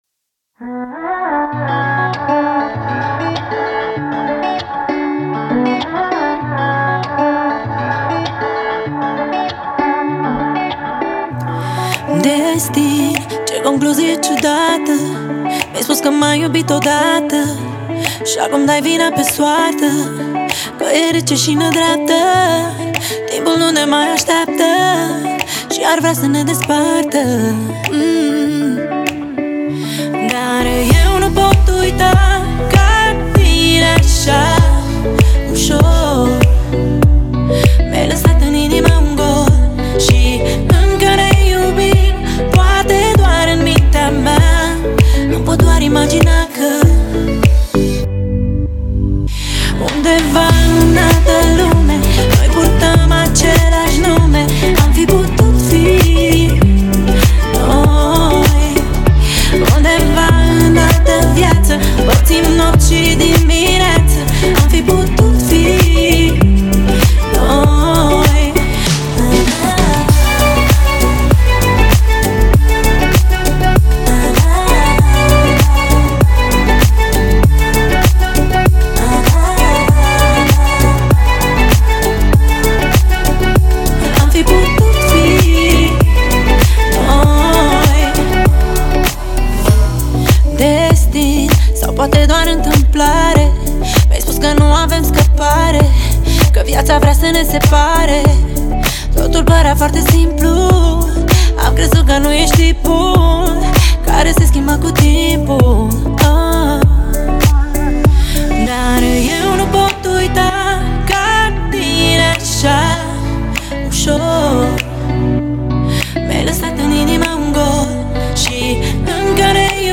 энергичная поп-песня румынской певицы